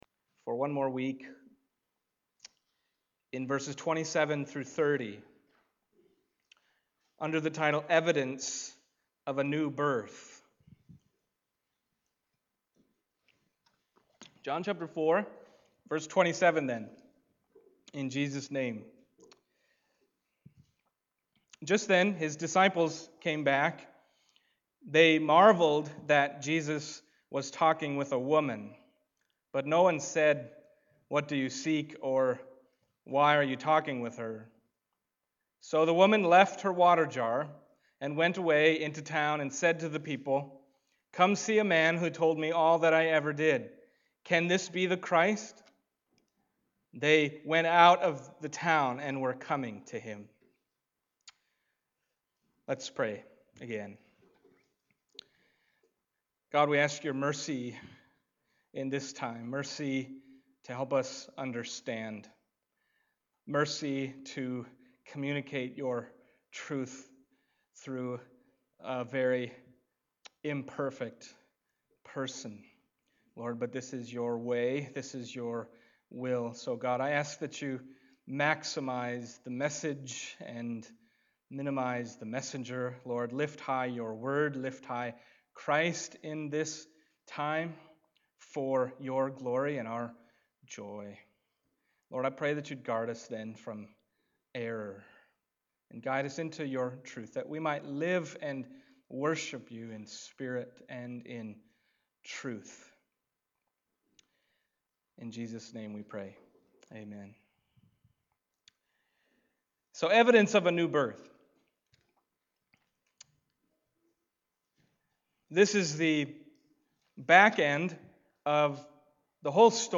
John Passage: John 4:27-30 Service Type: Sunday Morning John 4:27-30 « What Unites Us in Worship at FBC?